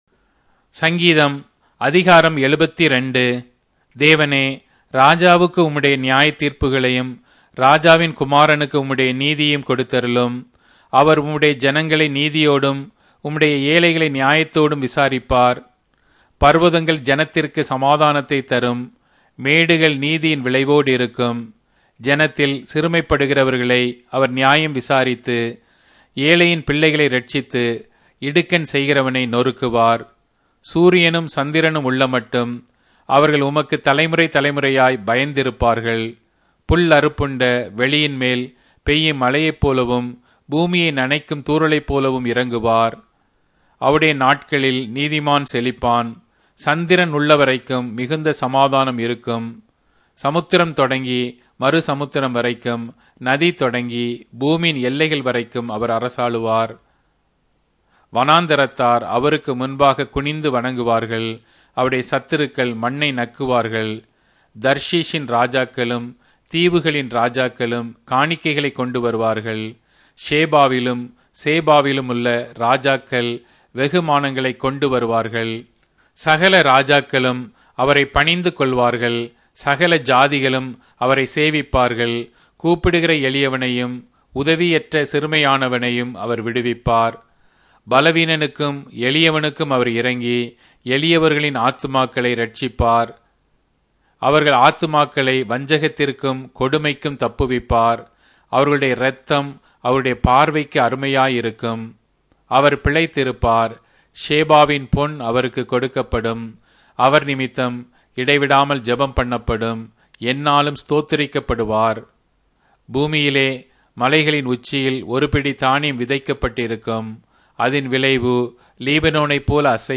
Tamil Audio Bible - Psalms 17 in Wlc bible version